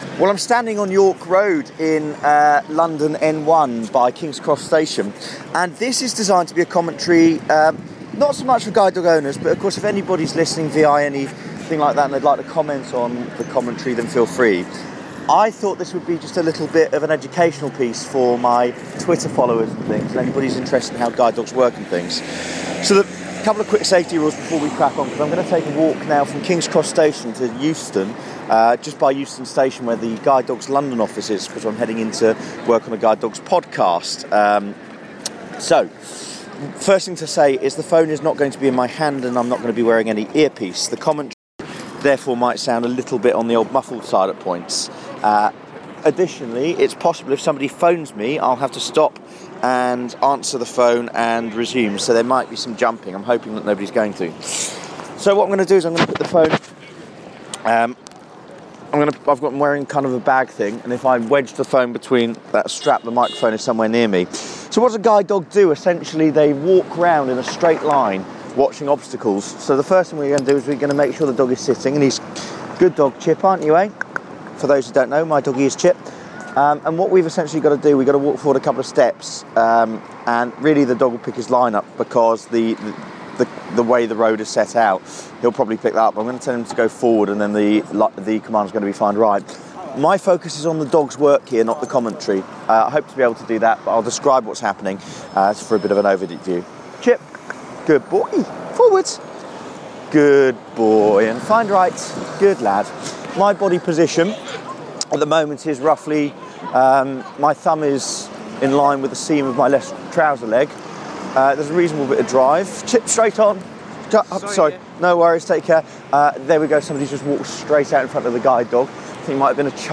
Audio (general interest) a guide dog walk with commentary in London